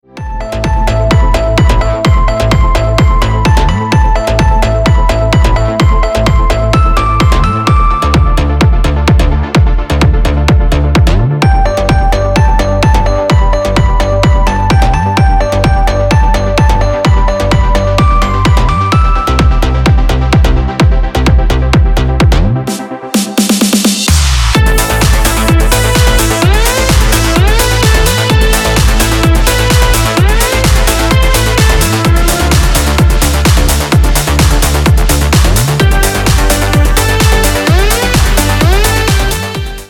Громкая мелодия на мобильный